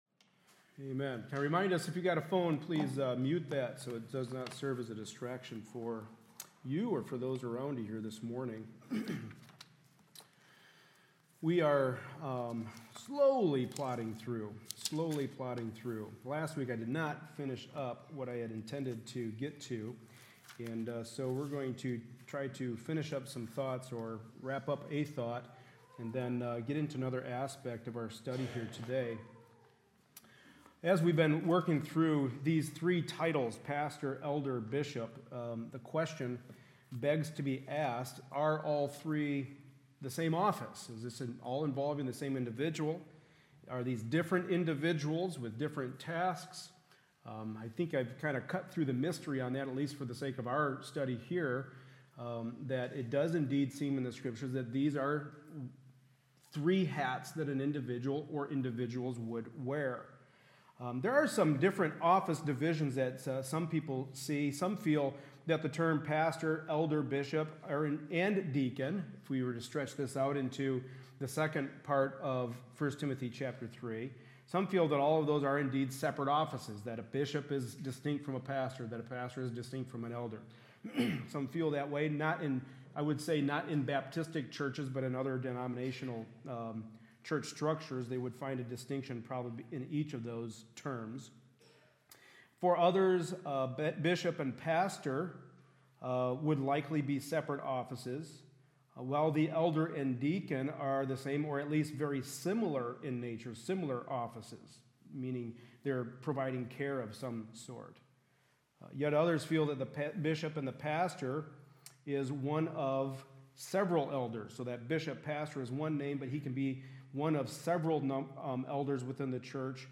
Series: The Book of 1st Timothy Service Type: Sunday Morning Service